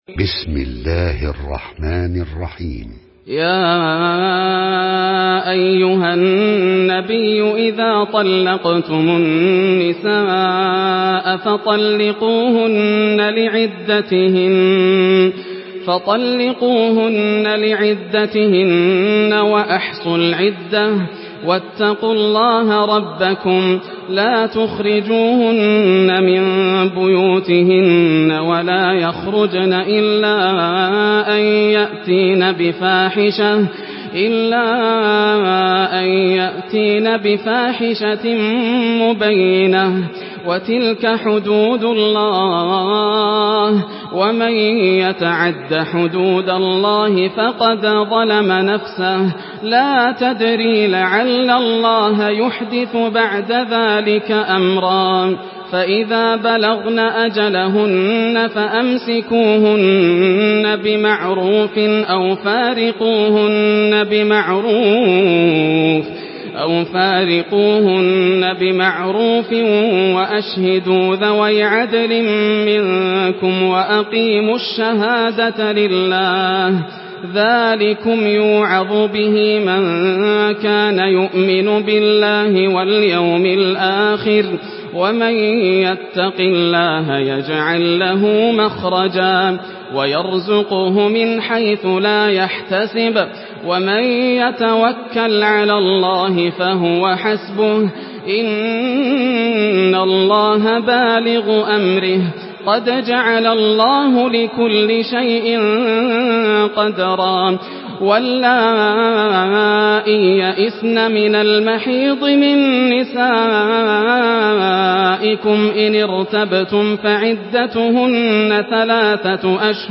Surah আত-ত্বালাক MP3 by Yasser Al Dosari in Hafs An Asim narration.
Murattal Hafs An Asim